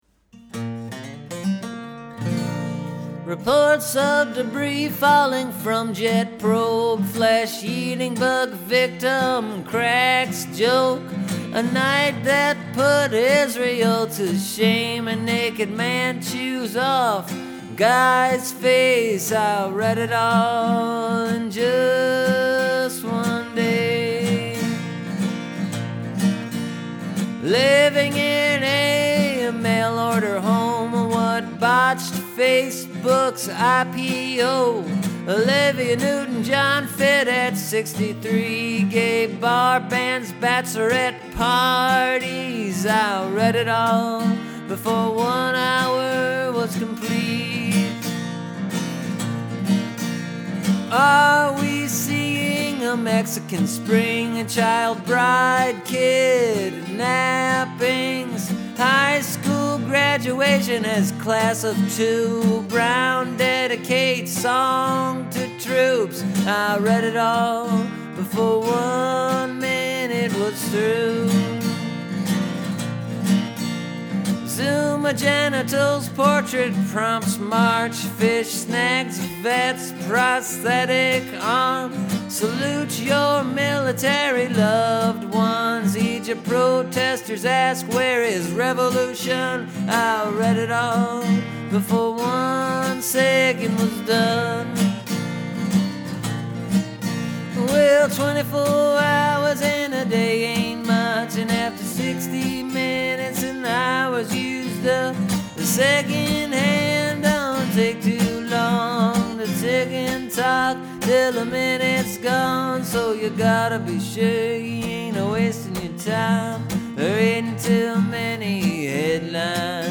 Well, here’s the re-recorded tune that I released this week, last year.
It’s a waltz now. I particularly enjoyed the mouth harp on this new one.